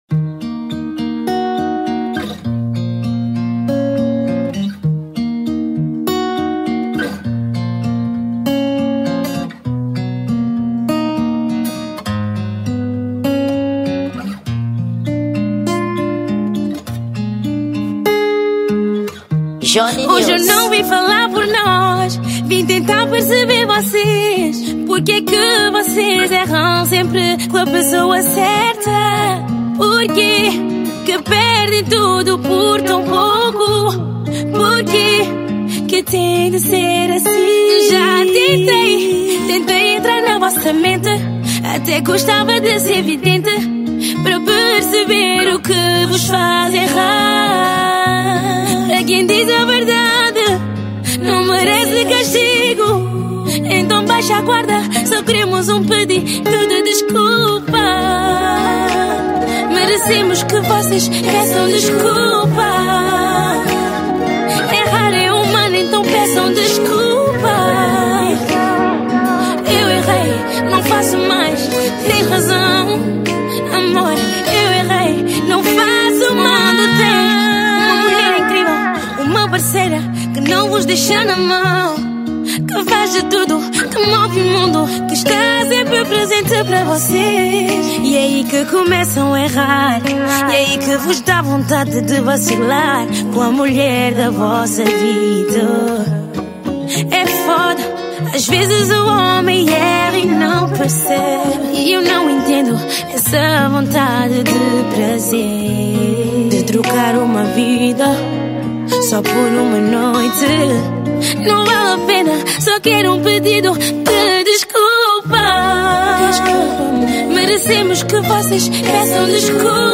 Gênero: Acústico